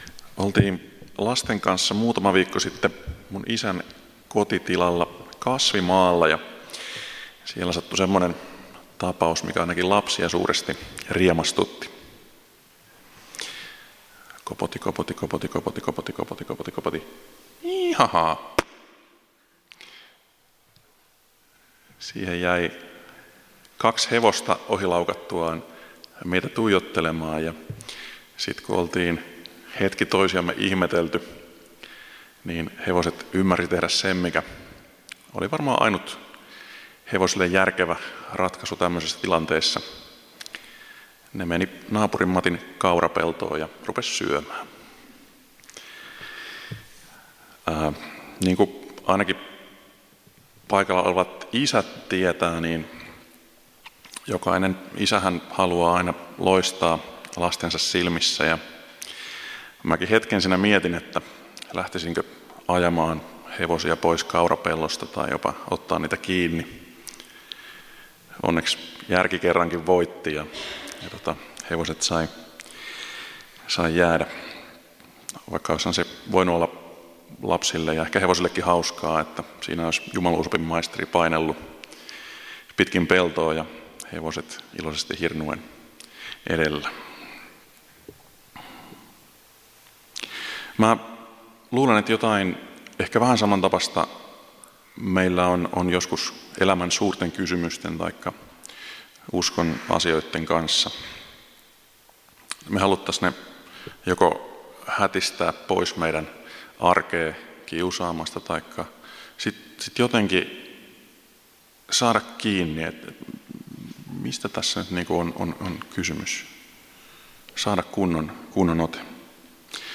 Loppuhartaus
Kokoelmat: Tampereen evankeliumijuhlat 2018